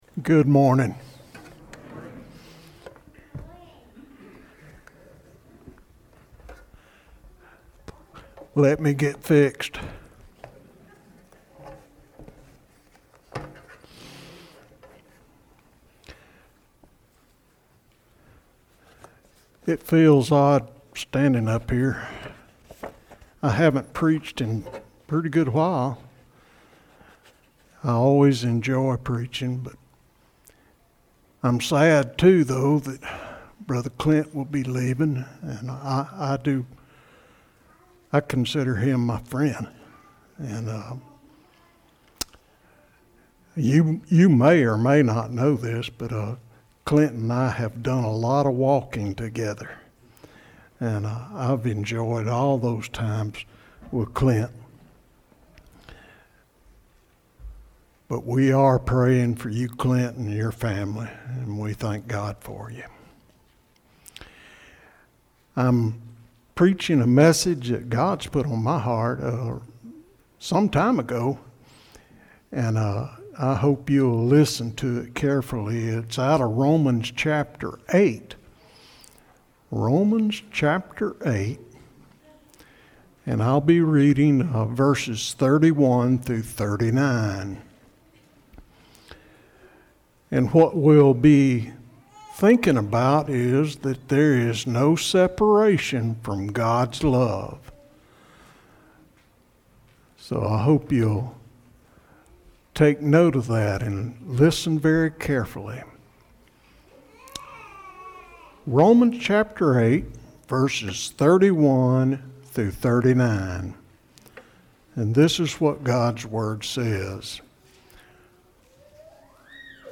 Service Type: Sermon only